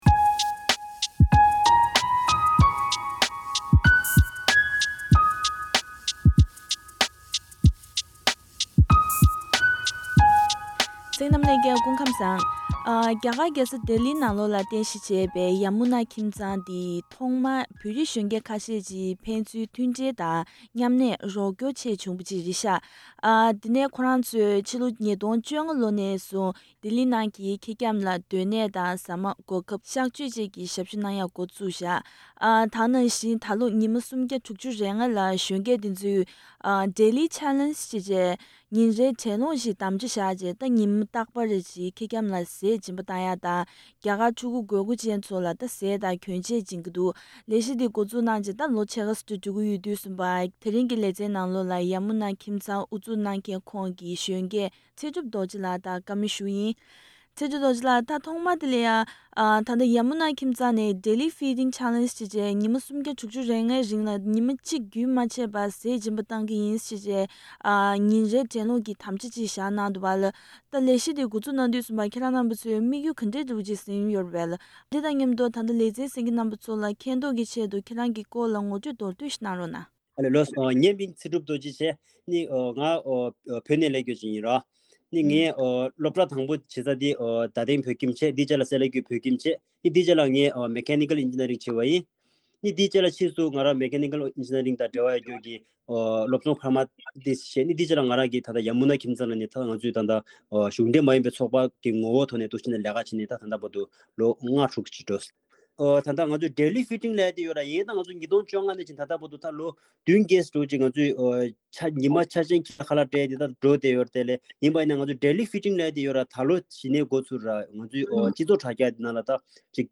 བཀའ་འདྲི་ཞུས་པ་ཞིག་གསན་གནང་གི་རེད།